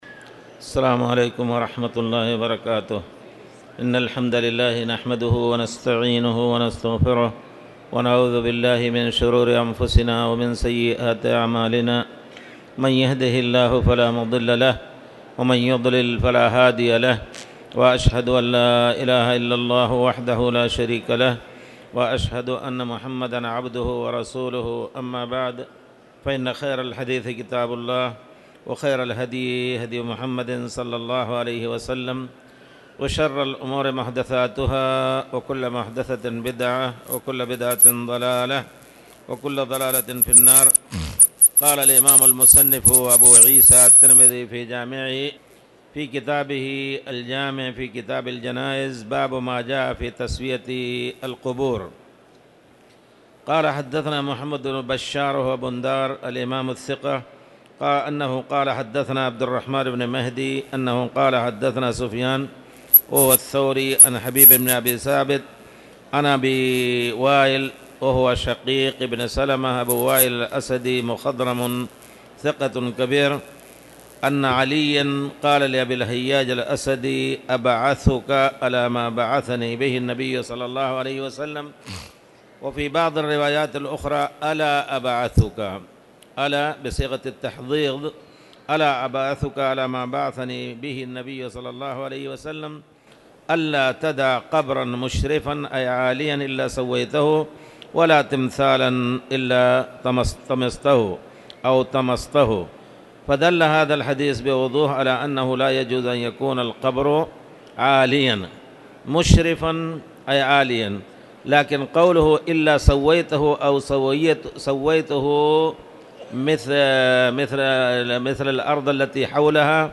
تاريخ النشر ٩ شوال ١٤٣٧ هـ المكان: المسجد الحرام الشيخ